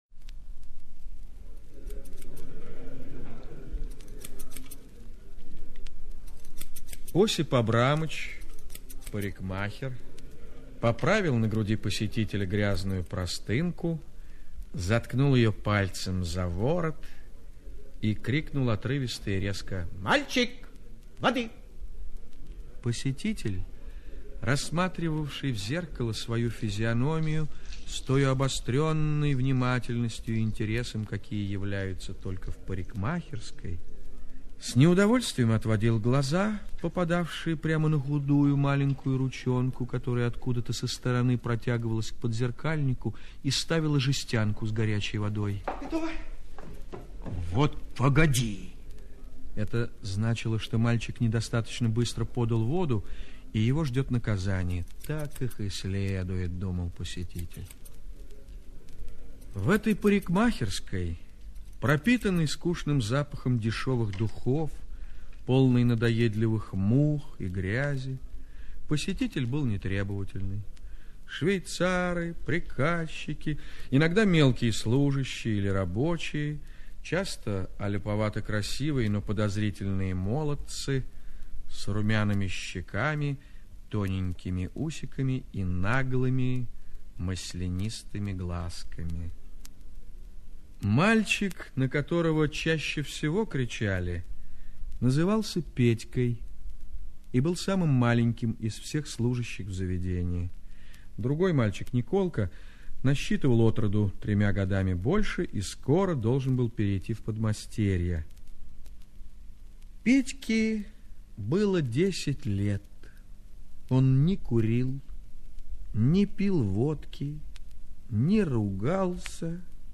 Петька на даче - аудио рассказ Андреева Л.Н. Рассказ про мальчика, которого мать из-за бедности отдала в подмастерья к парикмахеру.